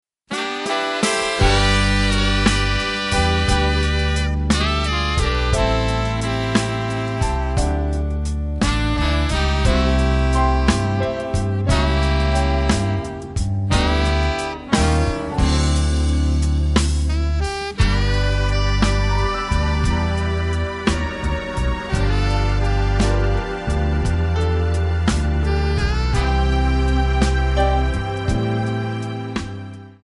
Backing track files: 1990s (2737)
Buy Without Backing Vocals